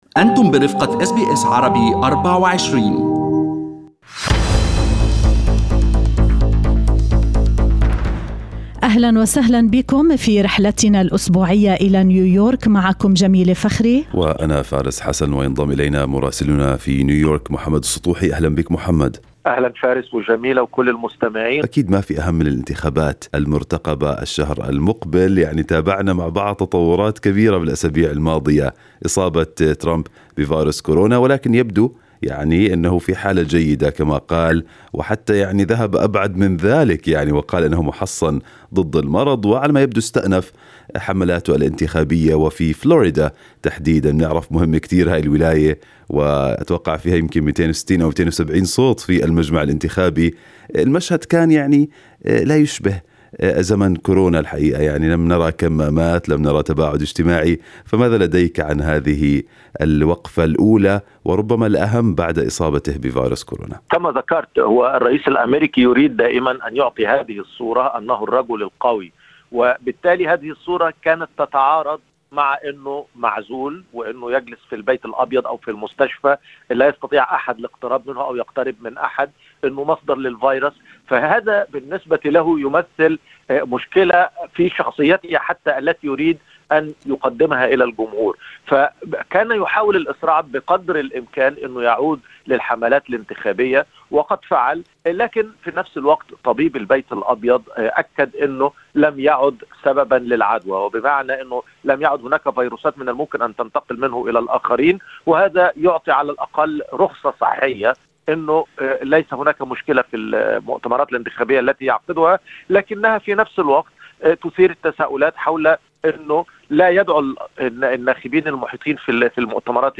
من مراسلينا: أخبار الولايات المتحدة الأمريكية في أسبوع 15/10/2020